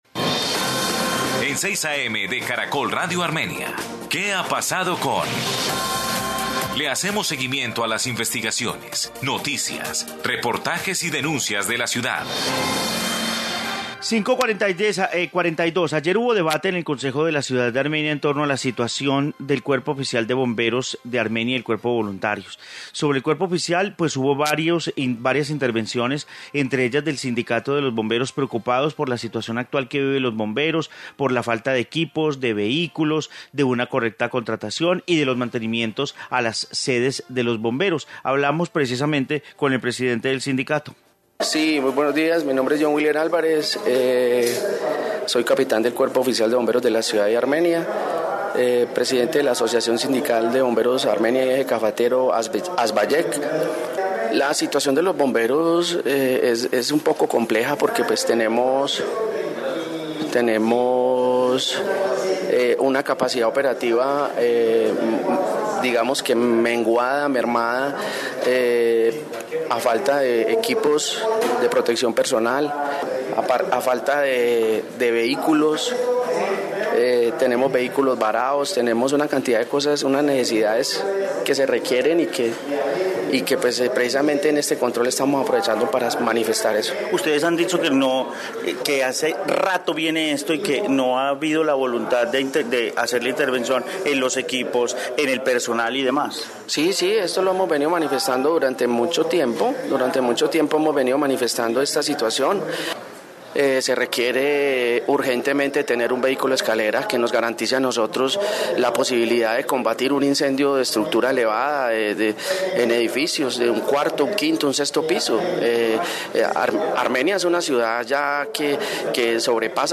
Informe debate de bomberos en Concejo, Armenia